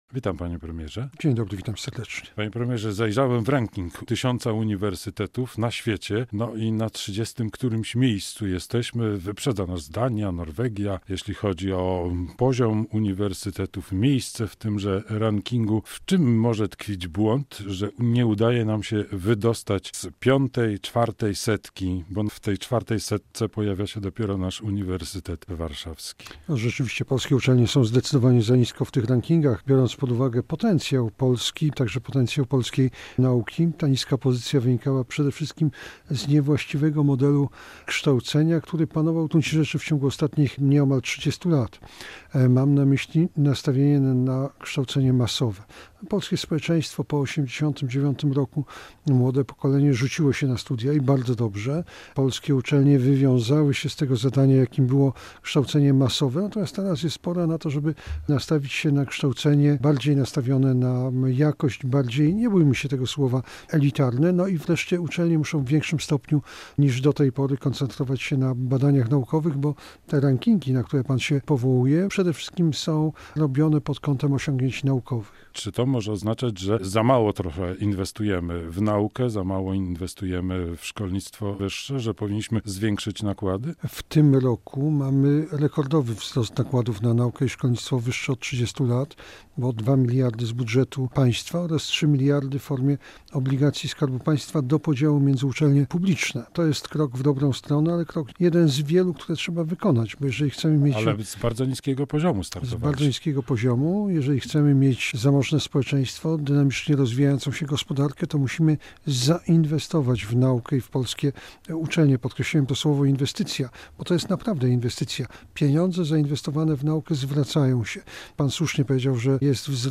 Radio Białystok | Gość | Jarosław Gowin [wideo] - wicepremier, minister nauki i szkolnictwa wyższego